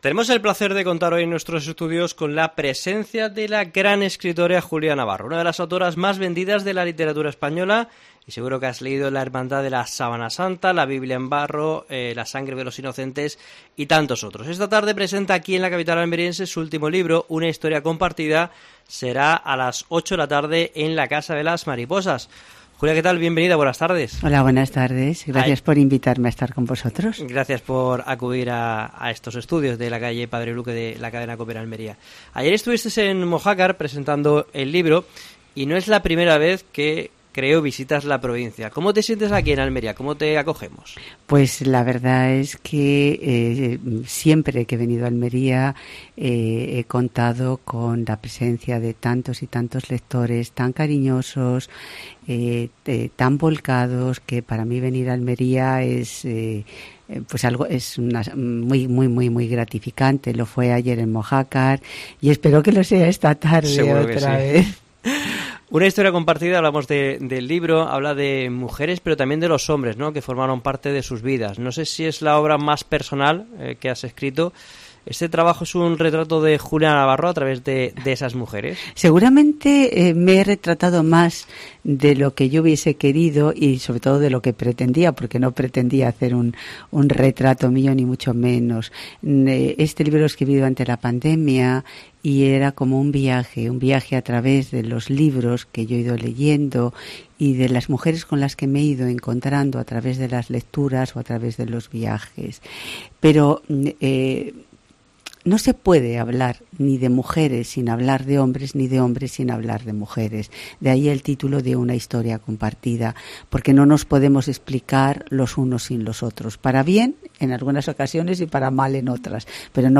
Julia Navarro presenta en COPE Almería su 'historia compartida'